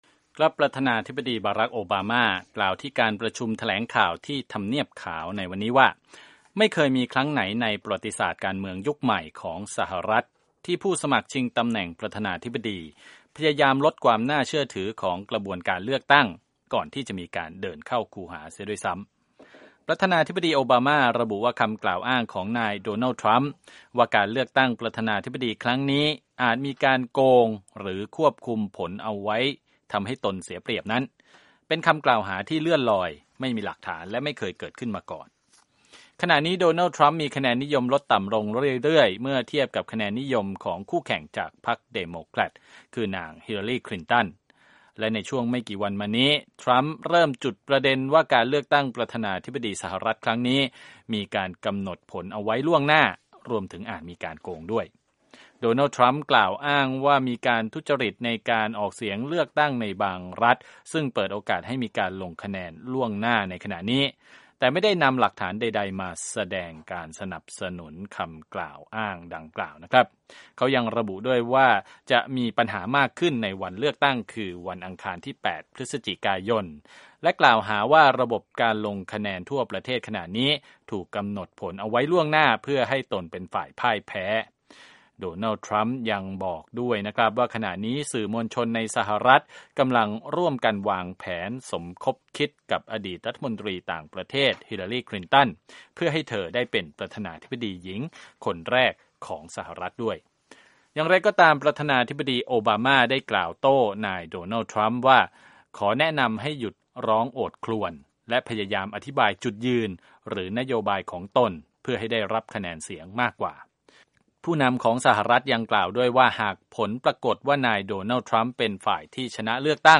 ประธานาธิบดีบารัค โอบาม่า กล่าวที่การประชุมแถลงข่าวที่ทำเนียบขาวว่า "ไม่เคยมีครั้งไหนในประวัติศาสตร์การเมืองยุคใหม่ ที่ผู้สมัครชิงตำแหน่งประธานาธิบดีพยายามลดความน่าเชื่อถือของกระบวนการเลือกตั้ง ก่อนที่จะมีการเดินเข้าคูหาเสียด้วยซ้ำ"